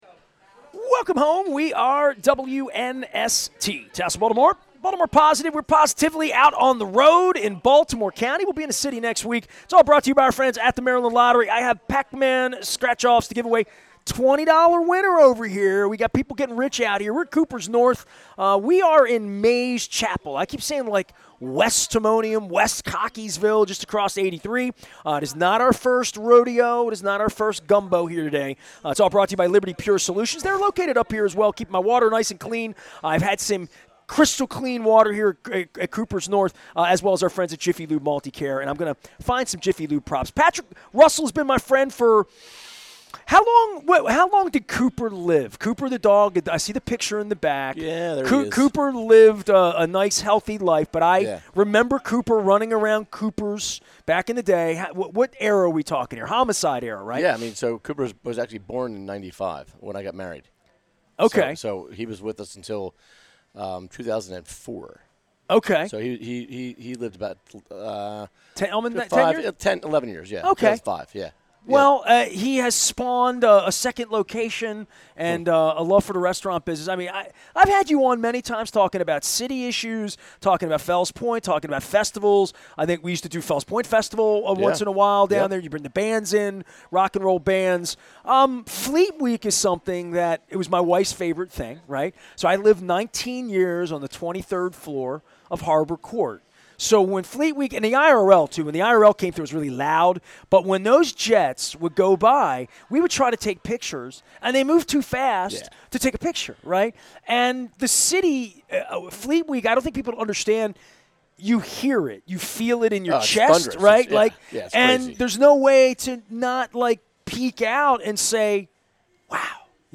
The Maryland Crab Cake Tour returned to Kooper's North in Timonium to discuss all of the reasons to get back to the original Kooper's Tavern in Fell's Point for this week's big Fleet Week celebration.